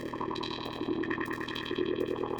C#m (D Flat Minor - 12A) Free sound effects and audio clips
• Chopped Velocity Texture.wav